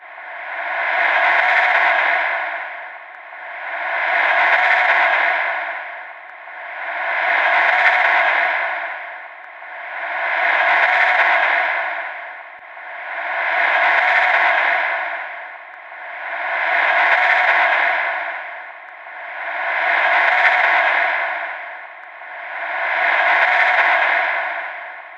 Descarga de Sonidos mp3 Gratis: drone.
spooky-drone.mp3